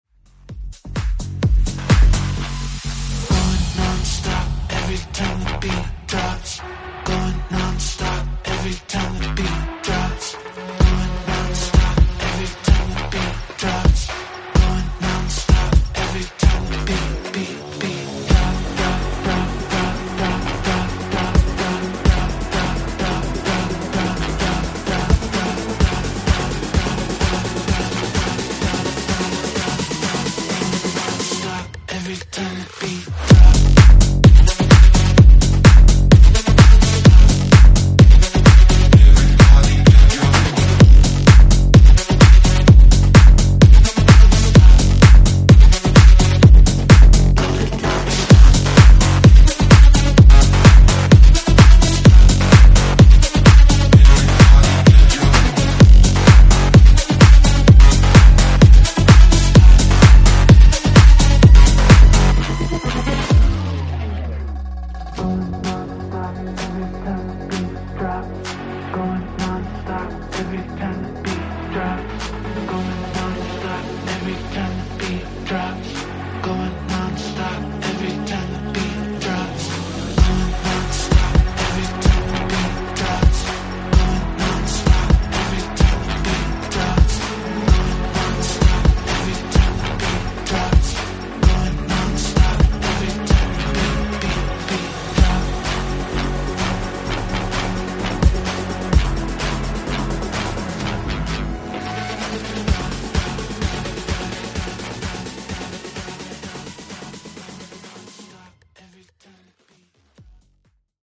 Genre: RE-DRUM Version: Clean BPM: 120 Time